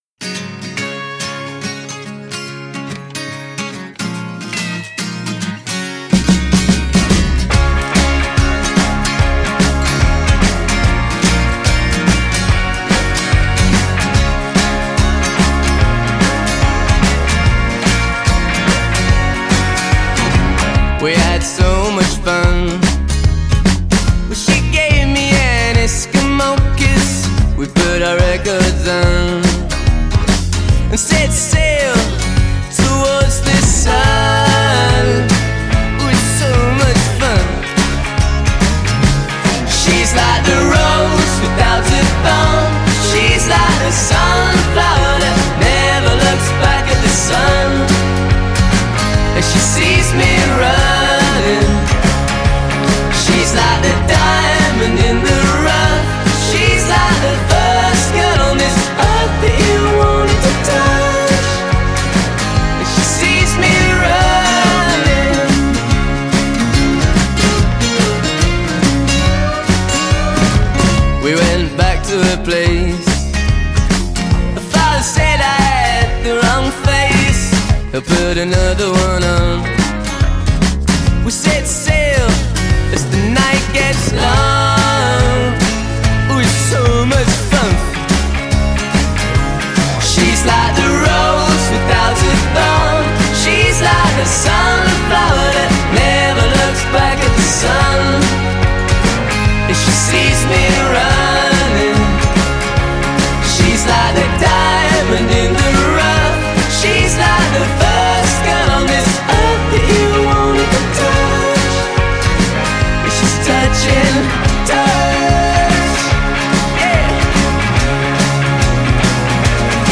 British pop band